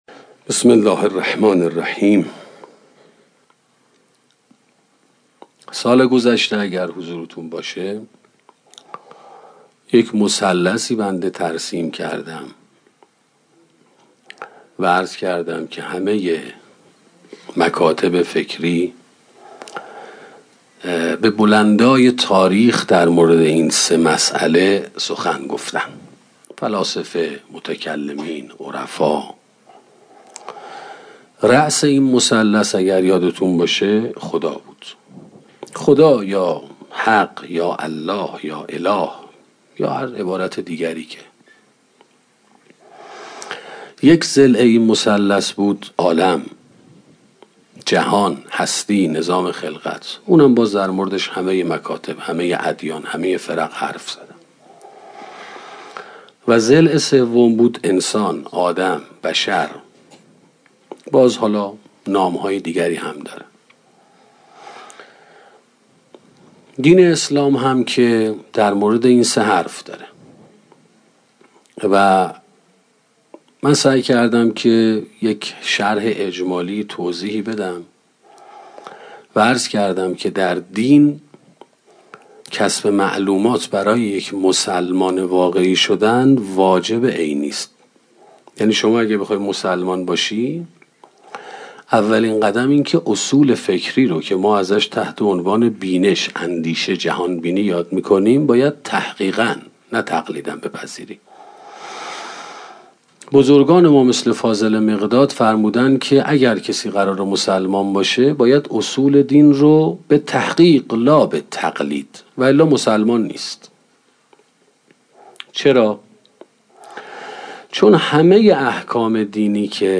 سخنرانی خانواده موفق 1 - موسسه مودت